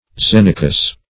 Xenicus \Xe"ni*cus\